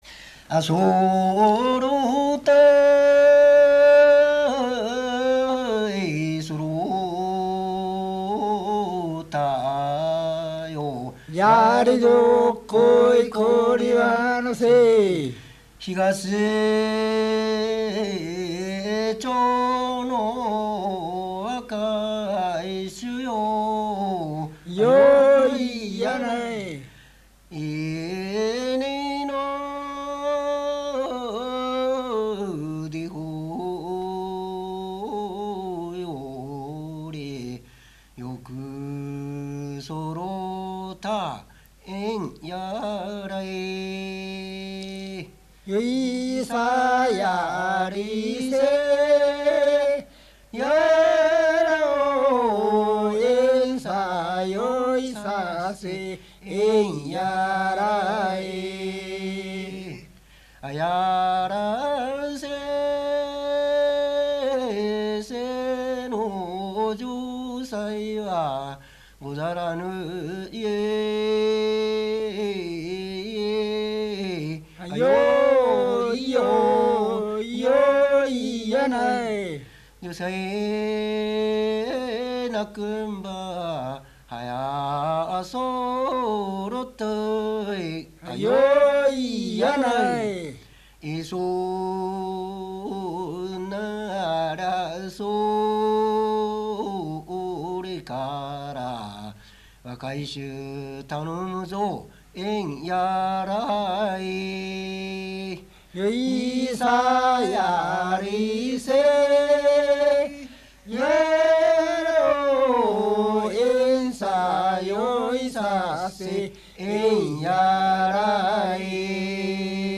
105 76 6 鴨川市 天津小湊町 浜萩
獅子舞の歌 舞歌